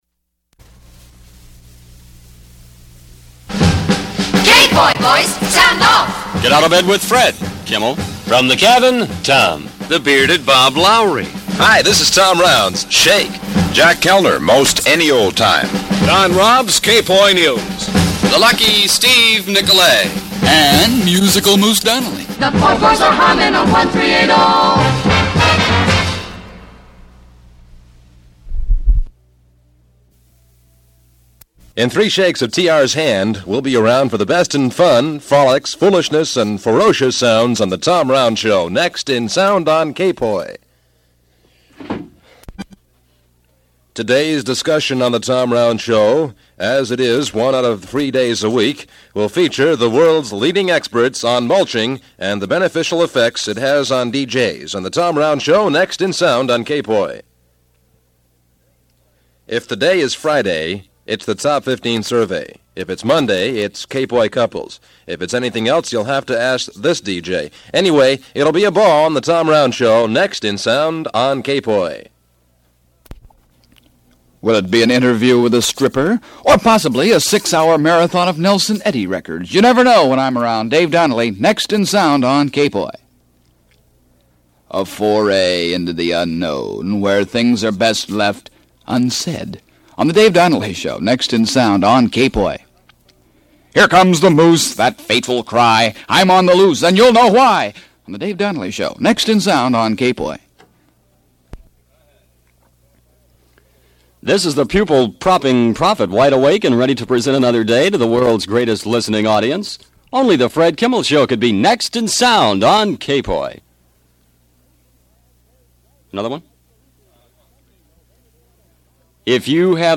KPOI-Honolulu promo reel. 1962-64.